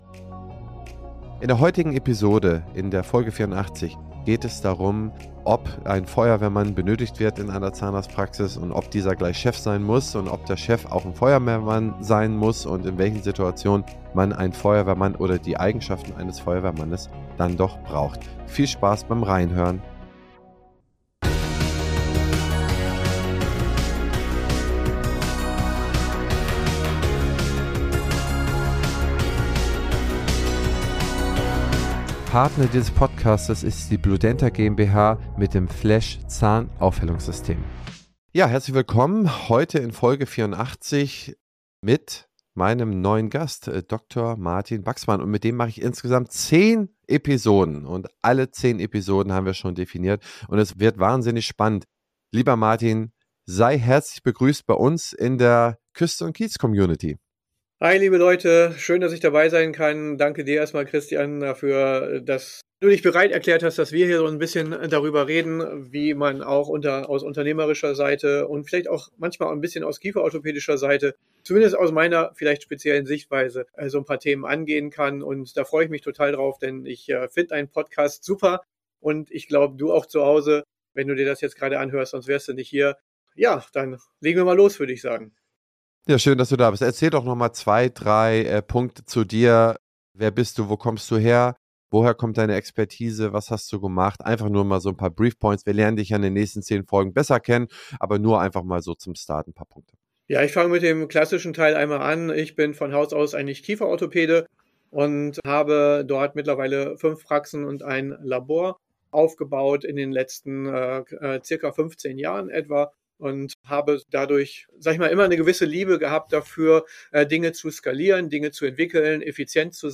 Freut euch auf eine Diskussion über die Rollenverteilung in Praxen, effektives Fehlermanagement und die Bedeutung von Protokollen und Vorbereitung.